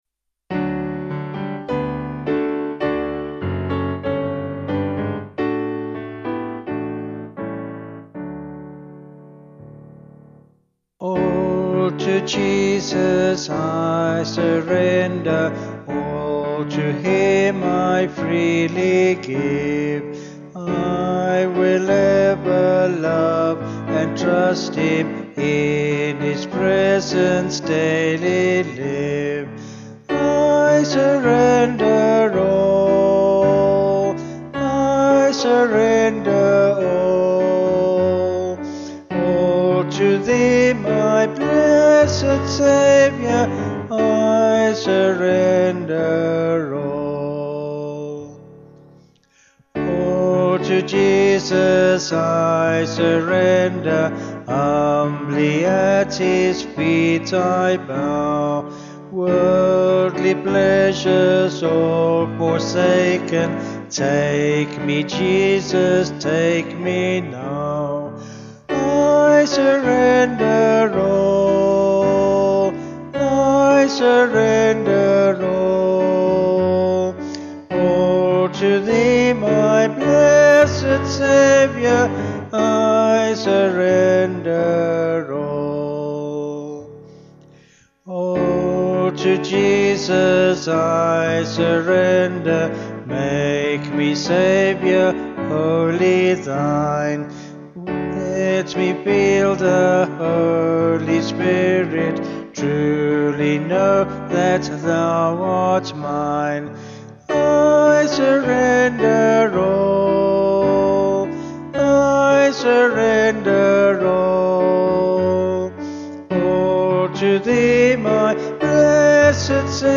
Vocals and Piano   226.2kb Sung Lyrics